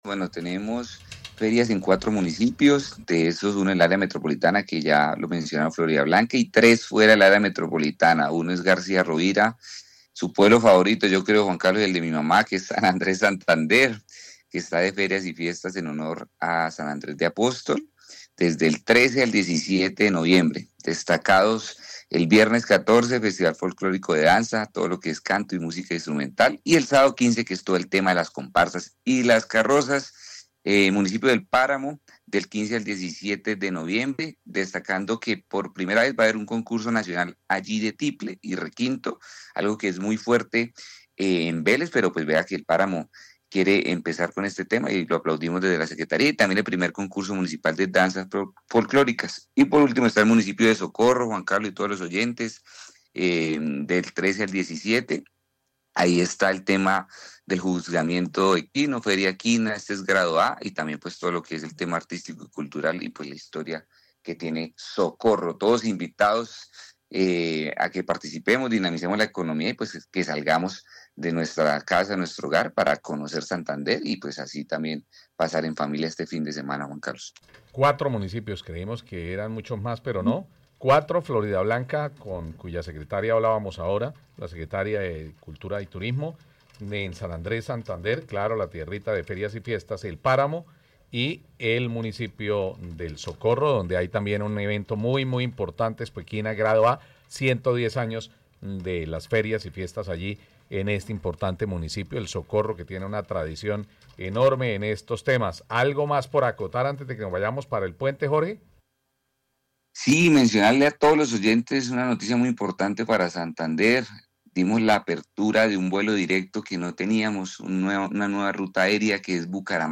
Jorge Rangel, secretario de cultura y turismo de Santander